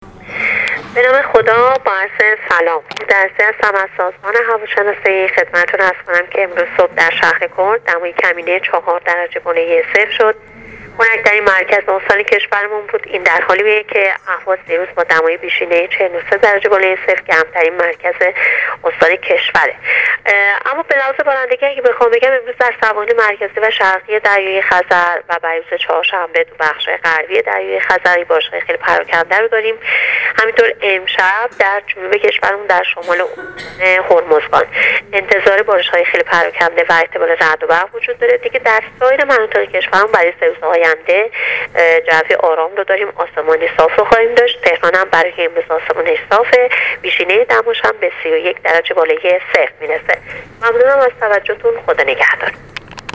دریافت فایل weather با حجم 1 MB برچسب‌ها: سازمان هواشناسی رادیو تلویزیون